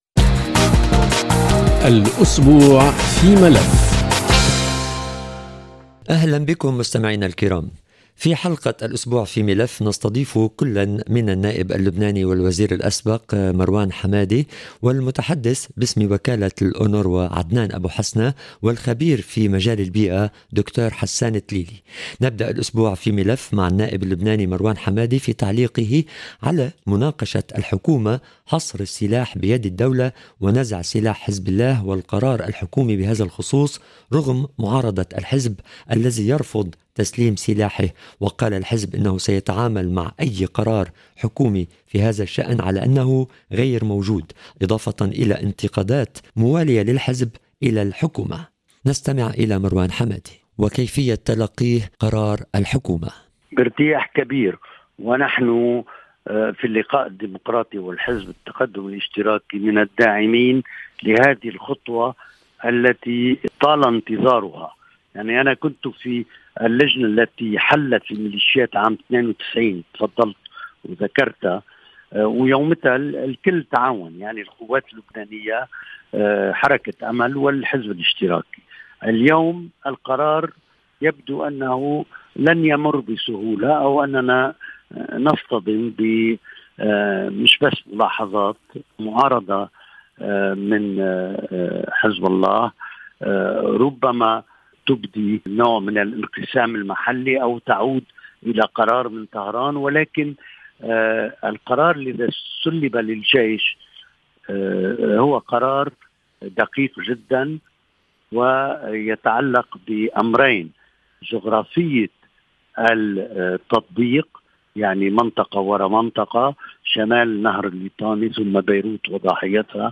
الاسبوع في ملف يستقبل عدداً من المسؤليين والخبراء في مجالات عدة. وفي حلقة هذا الاسبوع من البرنامج تتمحور بعض اللقاءات التي أجريناها، حول التطورات الحكومية في لبنان وقرار حصر السلاح بيد الدولة. كما نتناول موضوع ماساة اطفال غزة بين قتل وتجويع نتيجة الحرب الاسرائيلية على القطاع. ومن بين المواضيع نتناول أيضاً ظاهرة التلوث البلاستيكي في العالم، بمناسبة المؤتمر البيئي الذي عقد في جنيف.